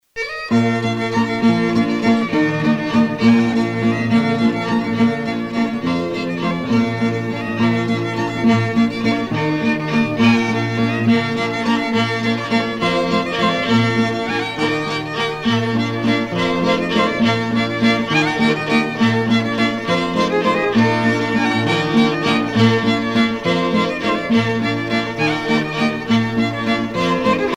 danse : valse